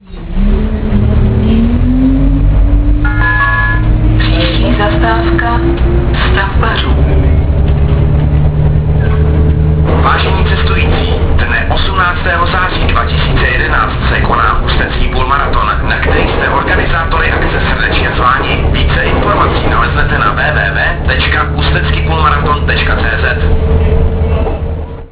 Hlášení zastávek ústecké MHD
Většina nahrávek byla pořízena ve voze ev.č. 516.
Na této stránce jsou kvalitnější zvuky, původní web obsahuje nahrávky horší zvukové kvality.